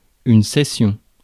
Ääntäminen
Ääntäminen France: IPA: [se.sjɔ̃] Tuntematon aksentti: IPA: /sɛ.sjɔ̃/ Haettu sana löytyi näillä lähdekielillä: ranska Käännös Konteksti Substantiivit 1. session tietojenkäsittely Suku: f .